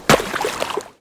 splash_mid.wav